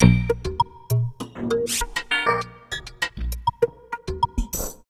66 ARP LP -R.wav